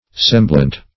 Semblant \Sem"blant\, a. [F. semblant, p. pr.]